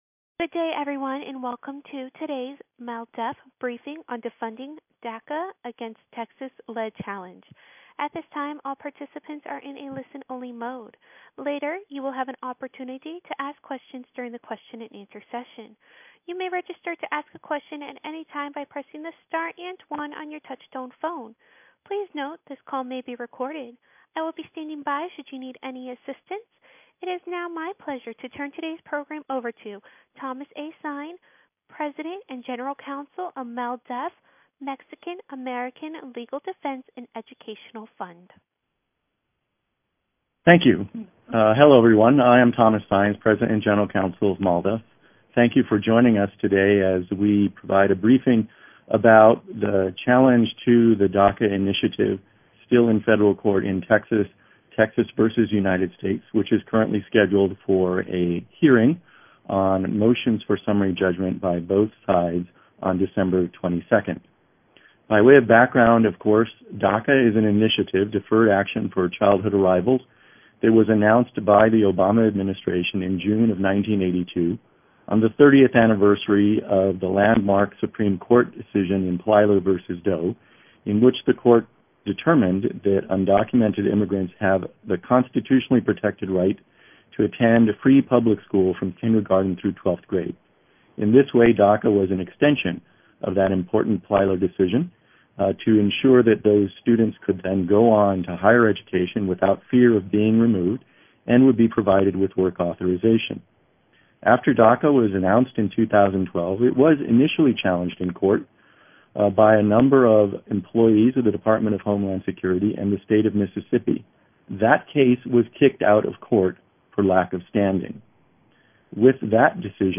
RECORDING OF MALDEF PRESS BRIEFING ON DACA AND TEXAS v. UNITED STATES - MALDEF